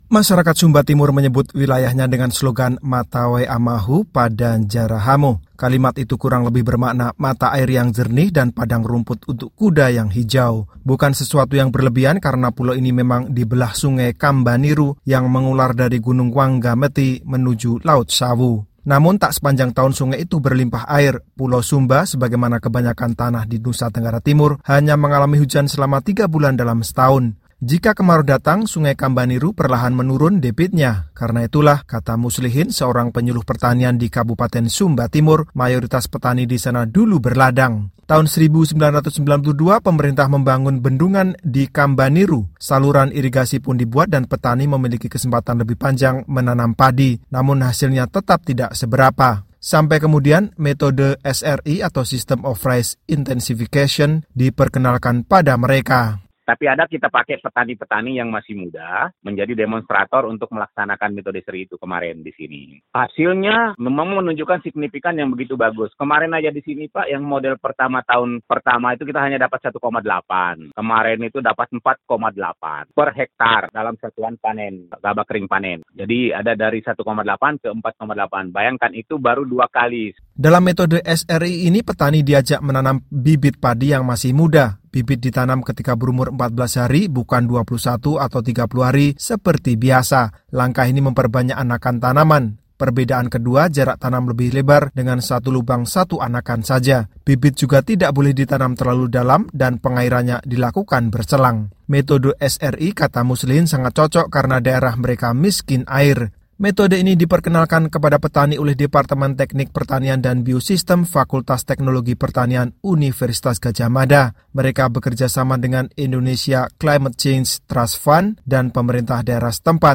Berikut laporannya.